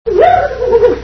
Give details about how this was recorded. Three Stooges Movie Sound Bites